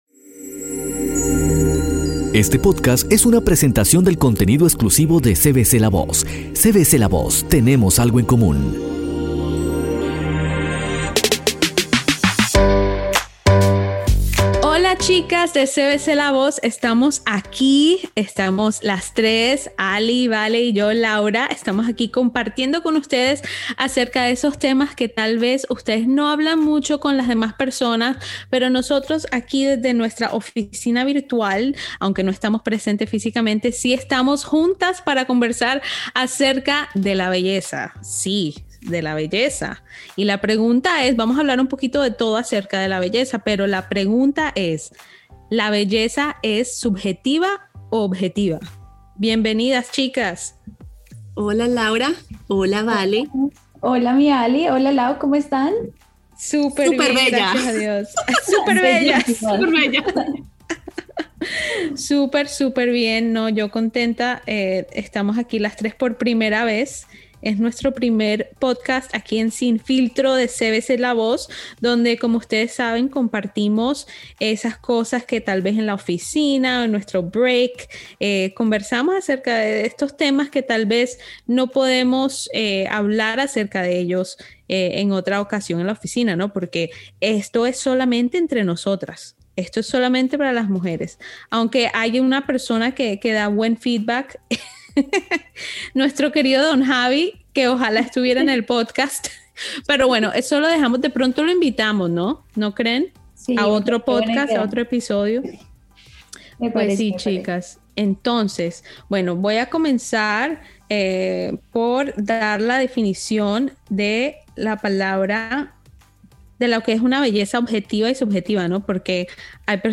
directamente desde nuestra oficina virtual.